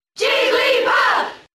Jigglypuff's cheer in the US versions of Brawl.
Jigglypuff_Cheer_English_SSBB.ogg